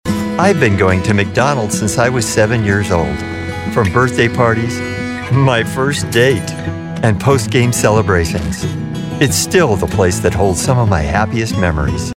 McDonalds/ Friendly, Approachable
Middle Aged